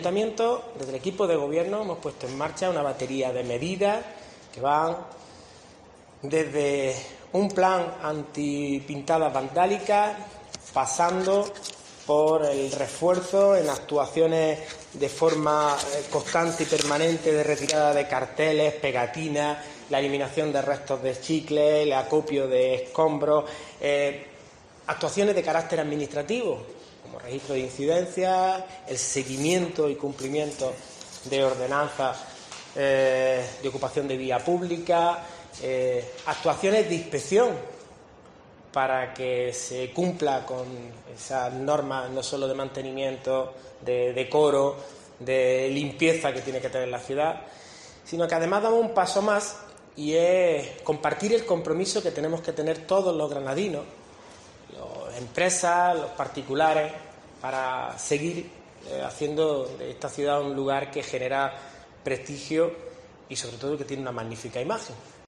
El alcalde presenta el plan de ornato de la ciudad